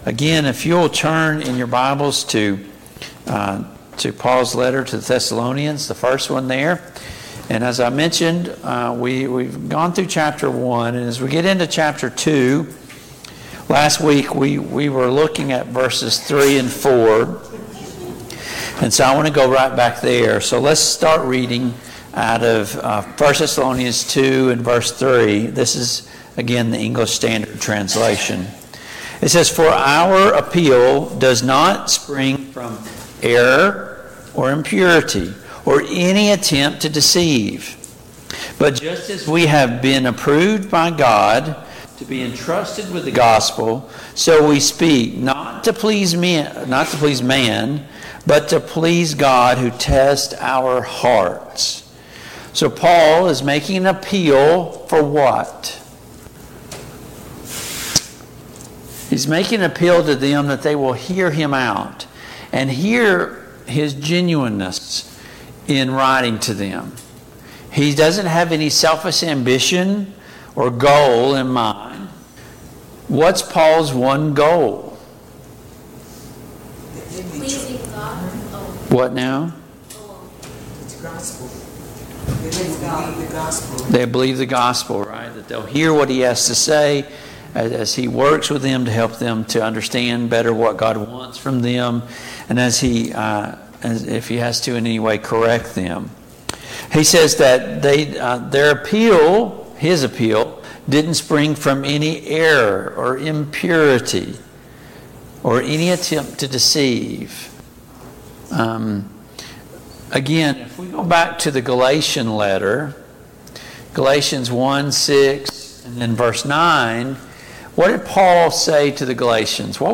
Passage: 1 Thessalonians 2:1-20 Service Type: Mid-Week Bible Study